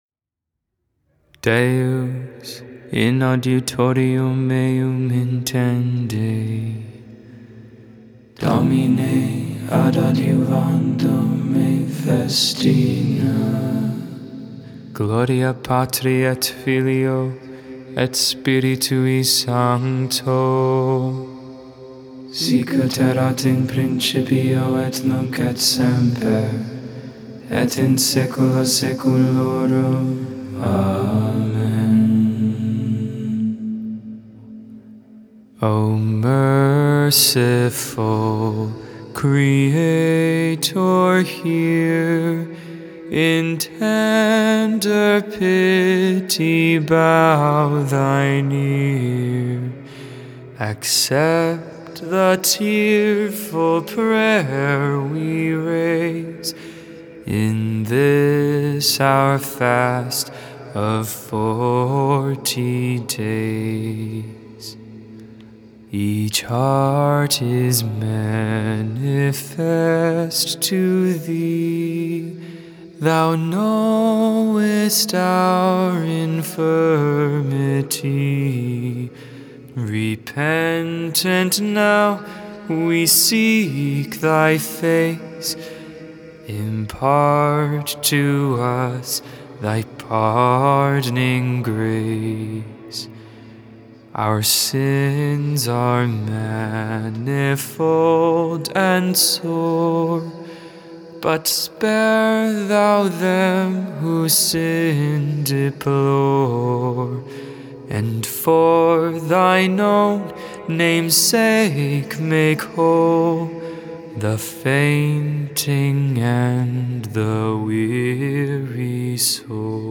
4.10.22 Vespers, Sunday Evening Prayer